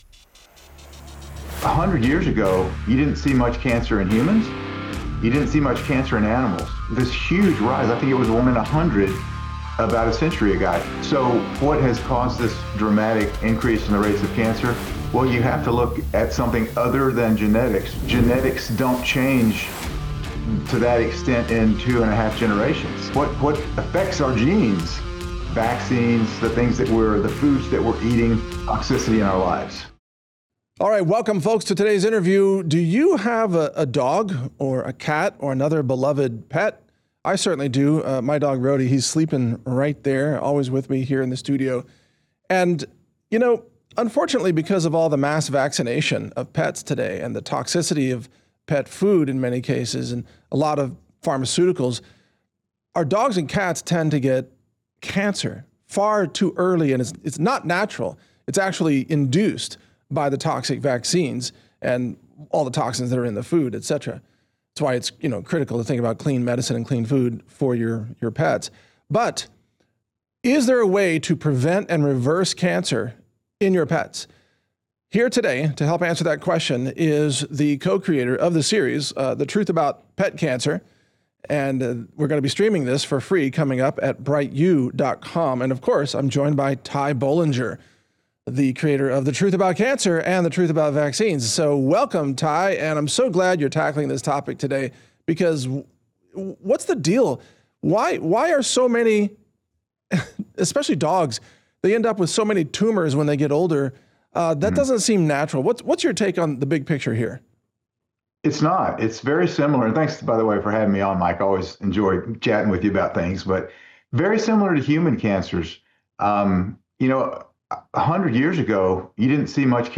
- Introduction and Purpose of the Interview (0:00)